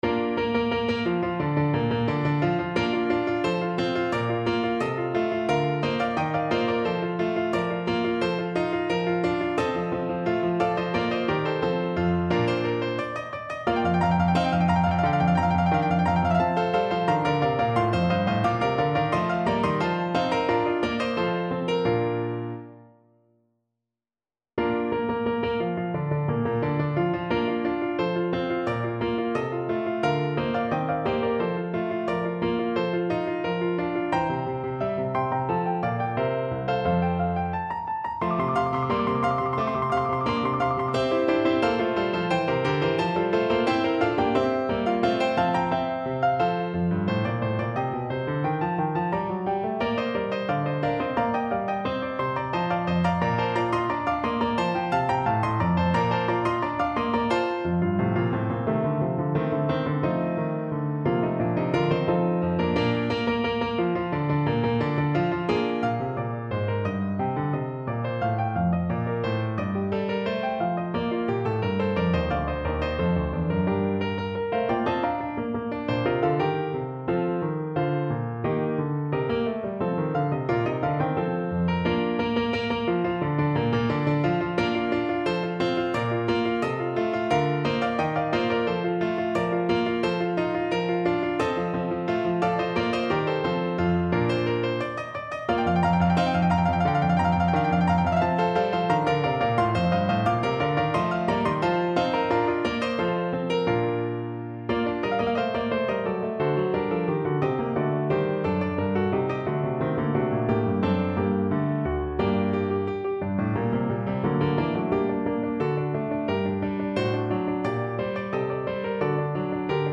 Free Sheet music for Clarinet
Play (or use space bar on your keyboard) Pause Music Playalong - Piano Accompaniment Playalong Band Accompaniment not yet available transpose reset tempo print settings full screen
Clarinet
Bb major (Sounding Pitch) C major (Clarinet in Bb) (View more Bb major Music for Clarinet )
4/4 (View more 4/4 Music)
Allegro = c.88 (View more music marked Allegro)
Classical (View more Classical Clarinet Music)